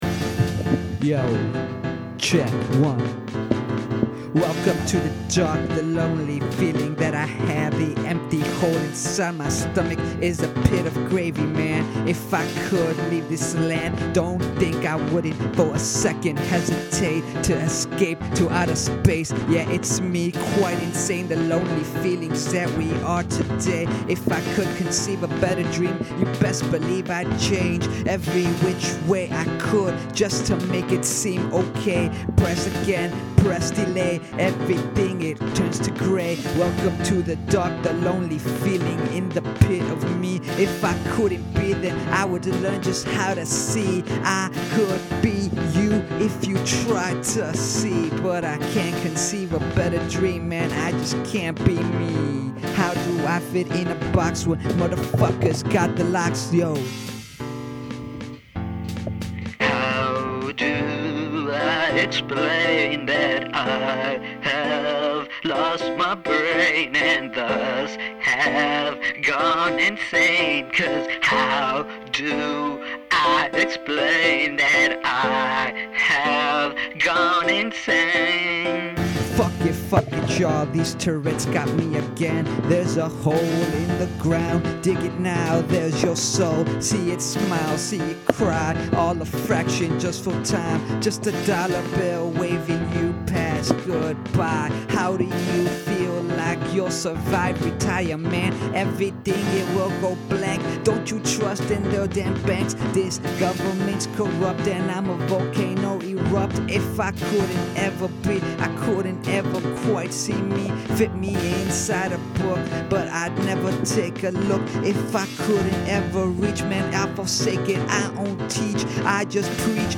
freestyles.